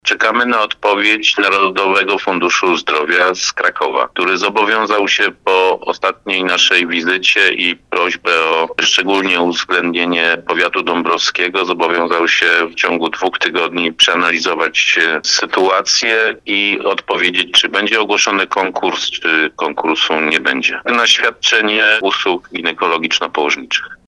Starosta dąbrowski Lesław Wieczorek mówi Radiu RDN Małopolska, że kluczowa będzie decyzja Narodowego Funduszu Zdrowia.